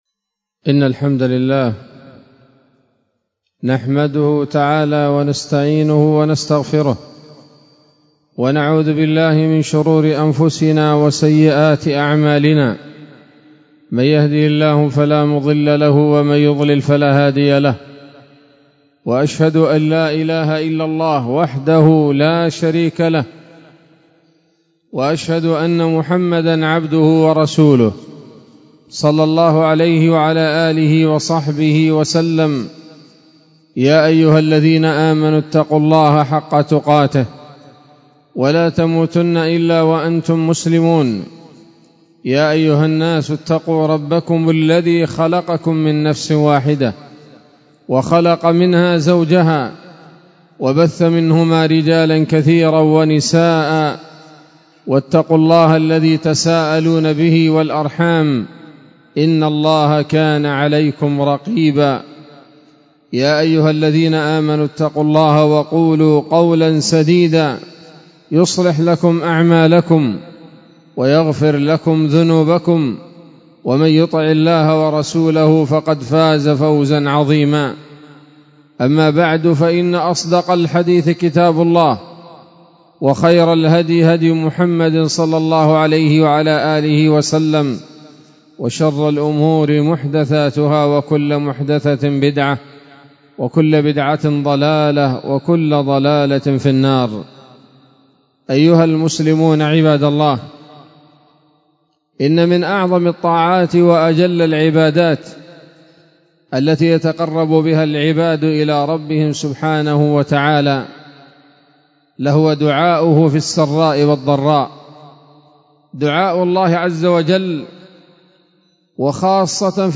خطبة جمعة بعنوان: (( شرح حديث: " اللهم اهدني فيمن هديت )) 12 رمضان 1445 هـ، دار الحديث السلفية بصلاح الدين